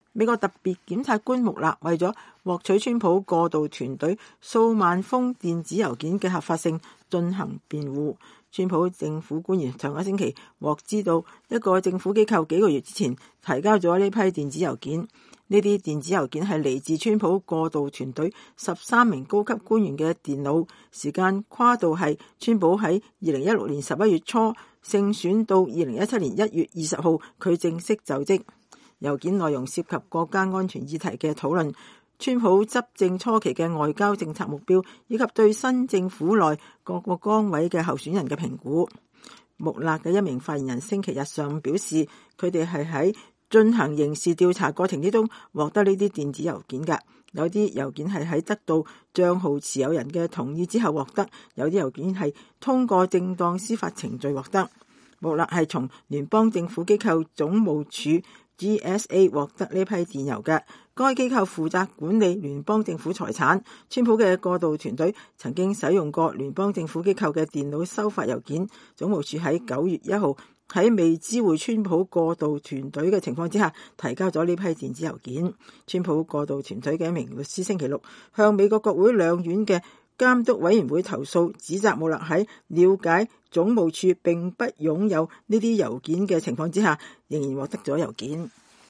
川普總統週日自大衛營返白宮時在南草坪對記者表示沒有計劃解僱特別檢察官穆勒。